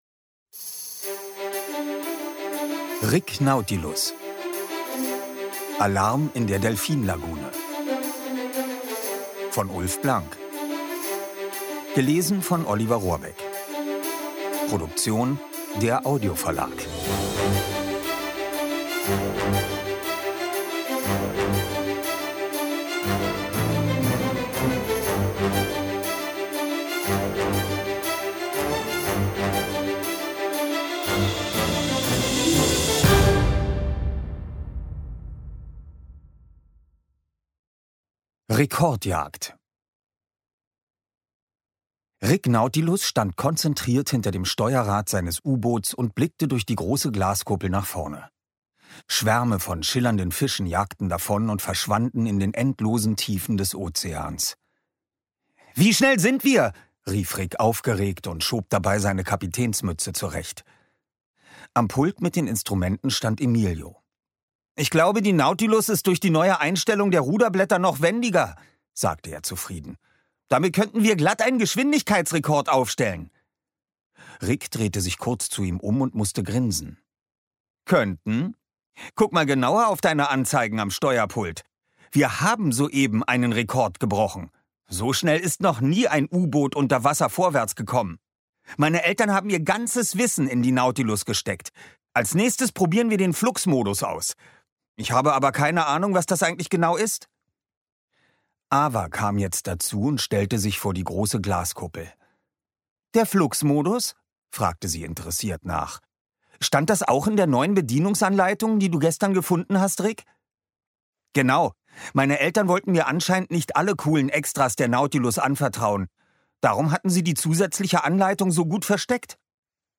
Rick Nautilus – Teil 3: Alarm in der Delfin-Lagune Ungekürzte Lesung mit Musik
Oliver Rohrbeck (Sprecher)